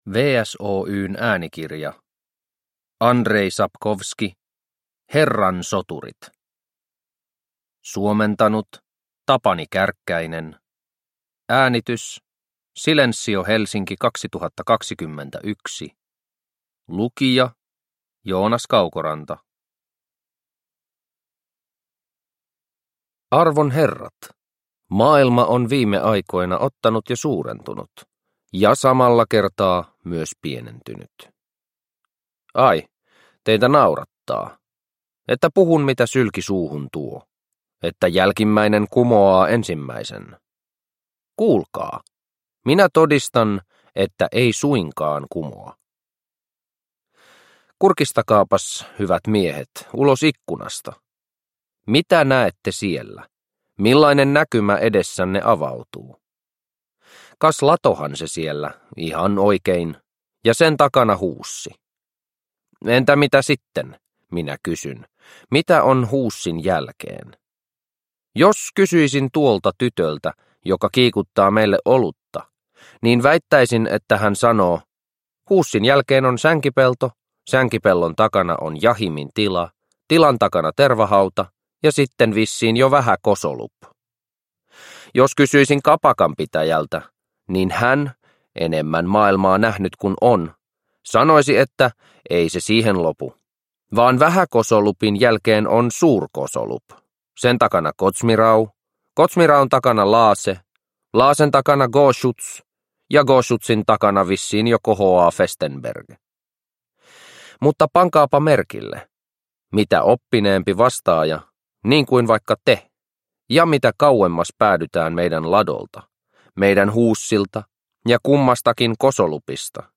Herran soturit – Ljudbok – Laddas ner